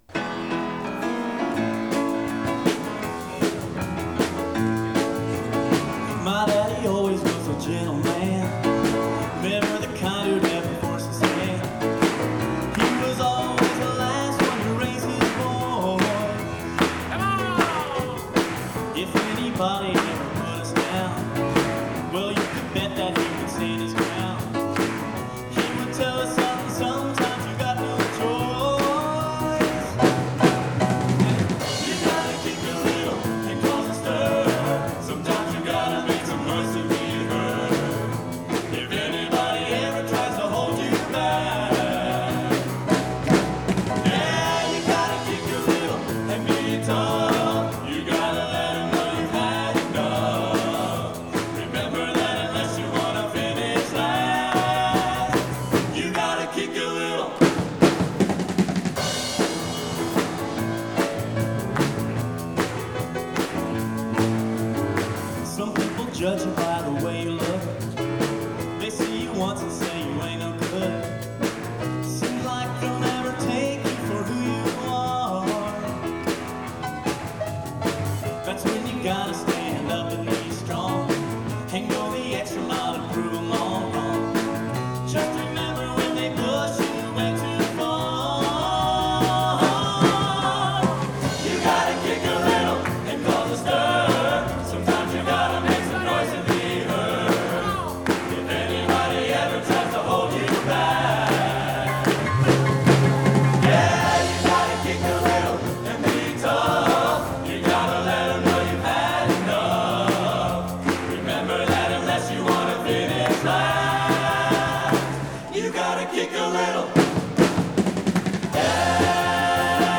Genre: Country/Western | Type: Specialty